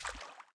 踩水zth070509.wav
通用动作/01人物/01移动状态/03趟水/踩水zth070509.wav